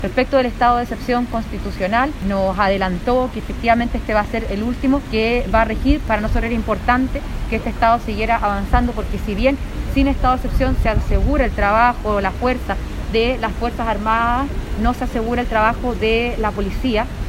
Una de las participantes fue la legisladora de Renovación Nacional por la región de Antofagasta, Paulina Núñez, quien sostuvo que en el tema migración es clave lograr acuerdos transversales.